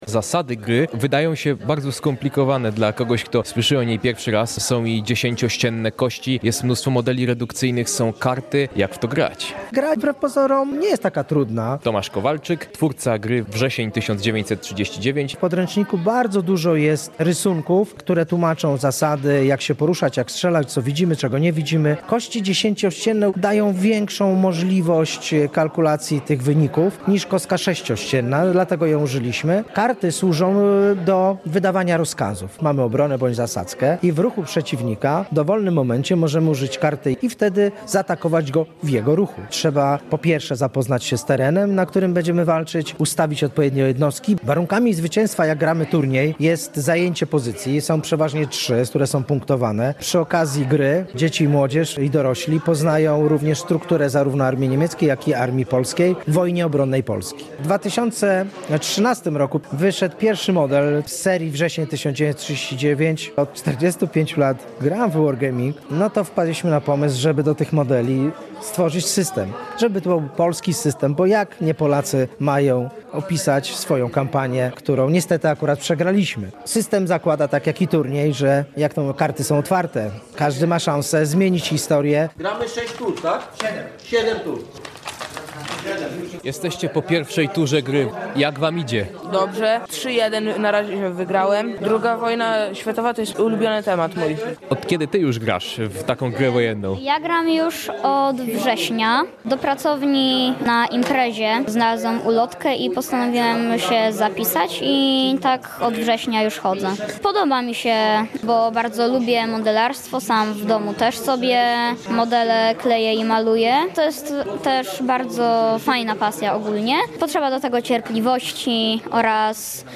Turniej historycznej gry bitewnej „Wrzesień 1939” w Tarnobrzegu • Relacje reporterskie • Polskie Radio Rzeszów